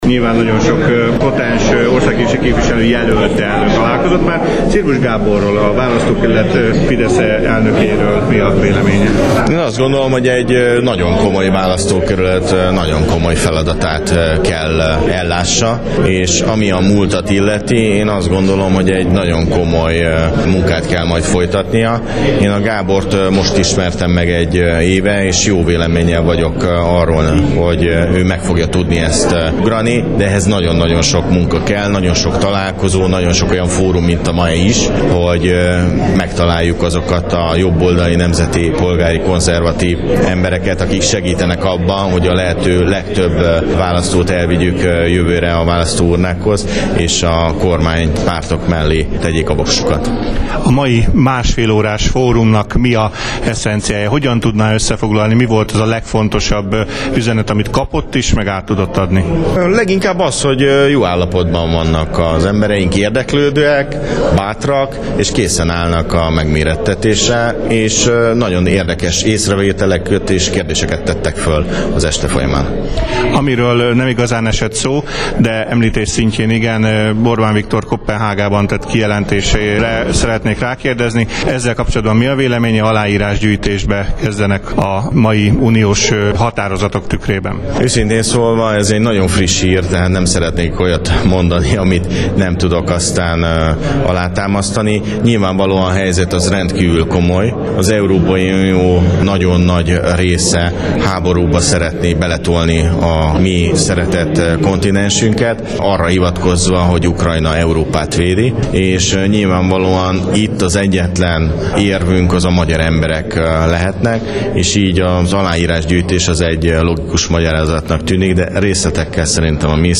A fórum végén gyorsinterjút kértünk tőle.